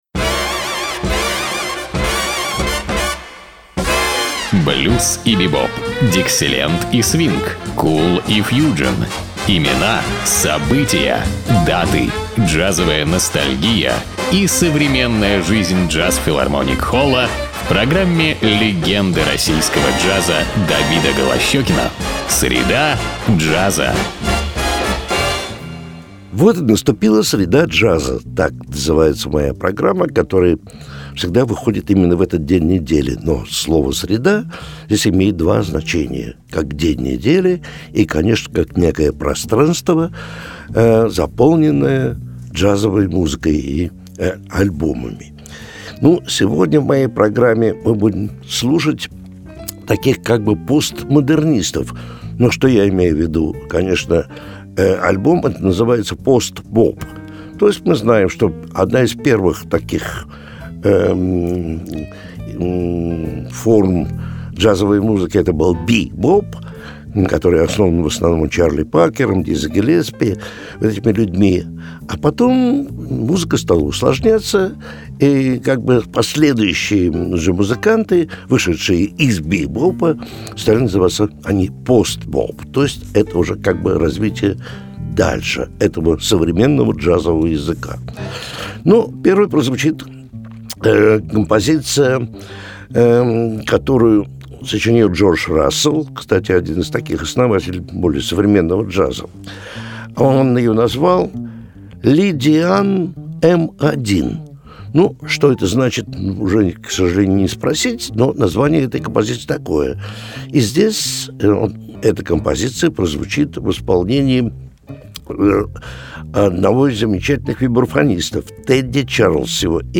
тенор-саксофон
фортепьяно
бас
ударные
тромбон
перкуссия